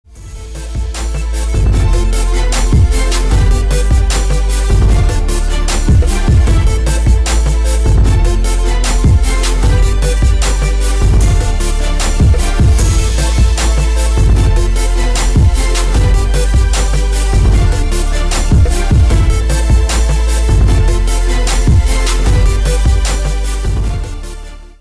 • Качество: 320, Stereo
расслабляющие
Расслабляющий рингтон.